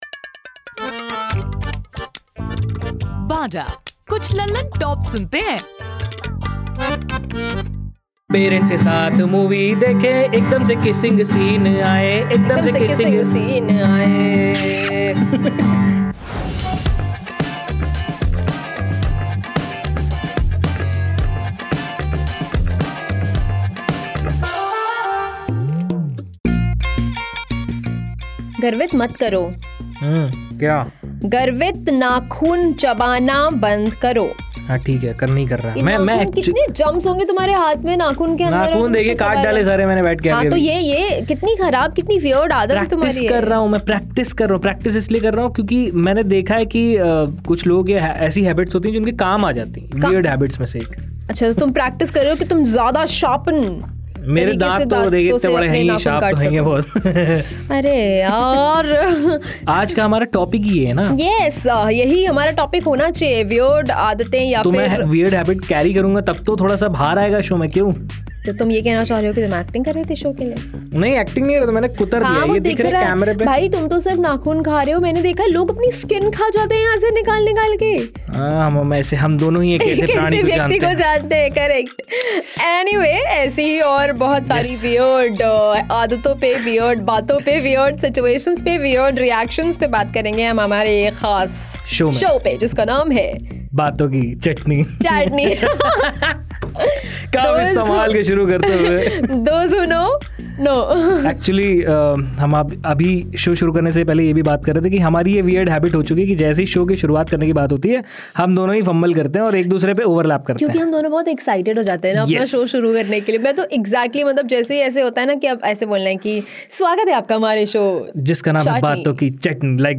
चैटनी, बातों की चटनी के इस एपिसोड में लोगों की वीयर्ड हैबिट्स को मज़ेदार तरीके से बताया जा रहा है. गर्दन चटकाना, पैर हिलाना या बैठे-बैठे तरह तरह की शक्ल बनाना ऐसी कई आदतें जो उनके अलावा वहां मौजूद कई लोगों को परेशान कर सकती है. एपिसोड के एन्ड में वीयर्ड हैबिट्स और सिचुएशंस पर एक पैरोडी सांग भी सुनने को मिलेगा.